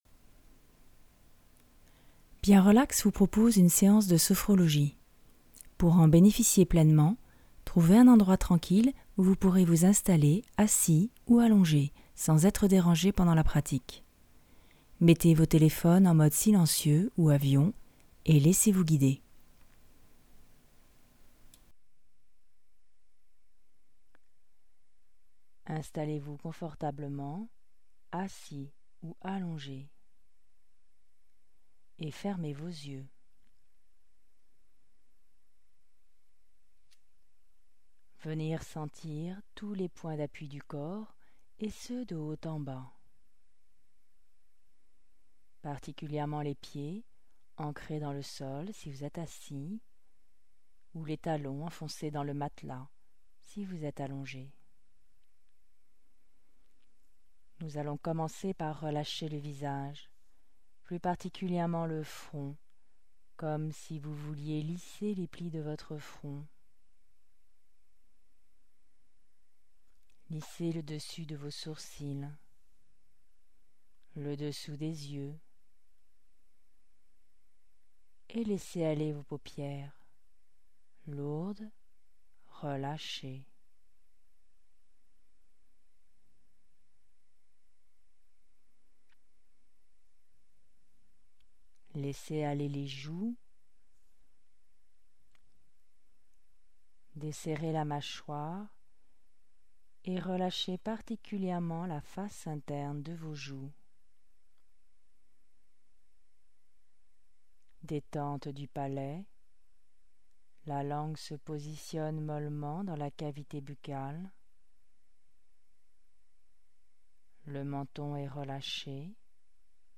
Genre : Sophrologie